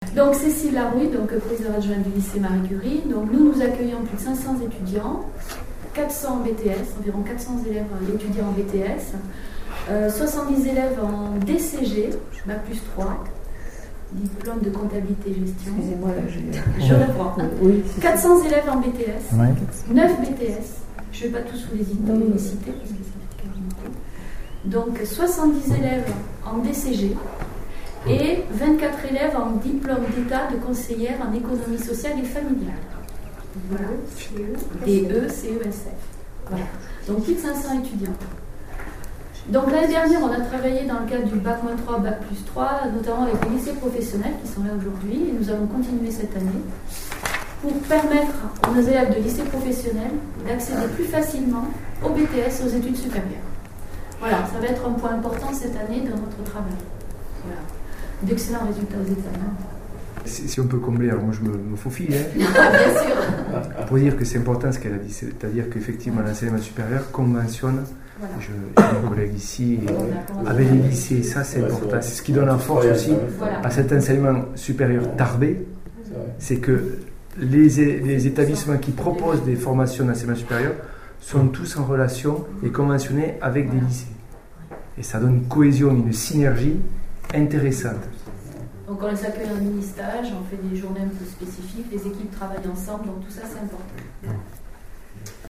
Chaque responsable d’établissement est ensuite intervenu.
Les interventions